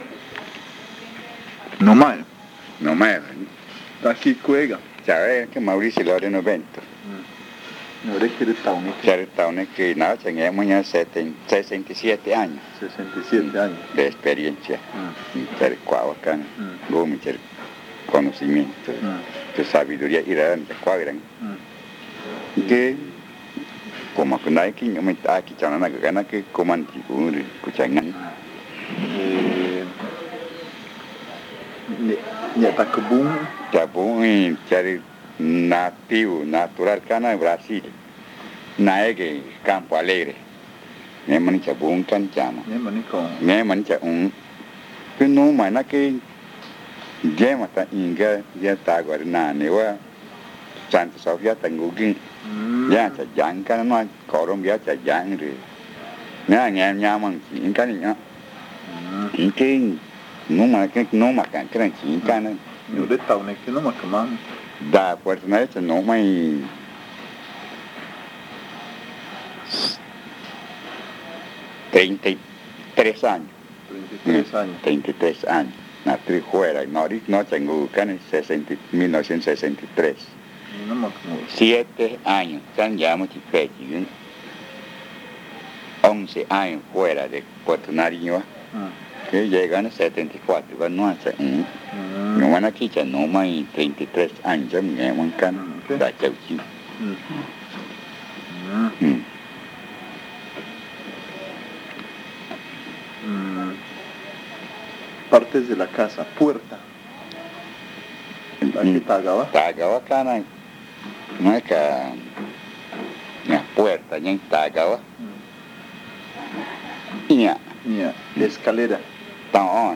Encuesta léxica y gramatical 11. Puerto Nariño n°2
El audio contiene los lados A y B.